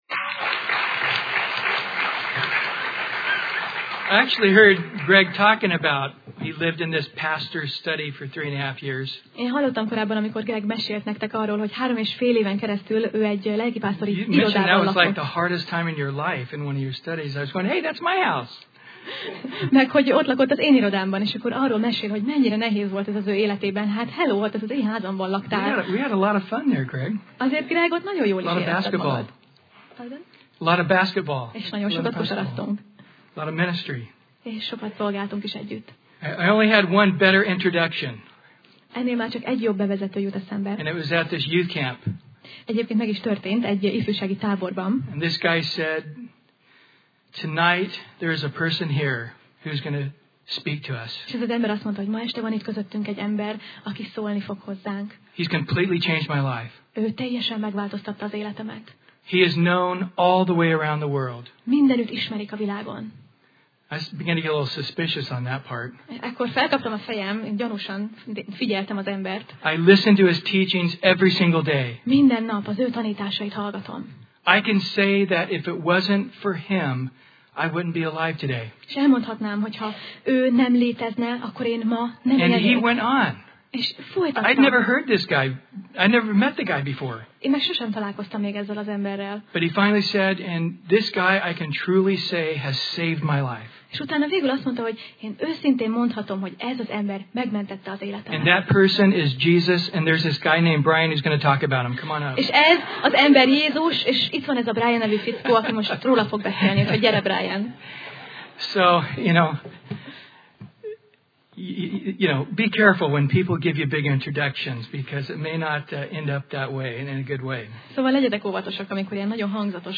Tematikus tanítás Passage: 2Sámuel (2Samuel) 6 Alkalom: Szerda Este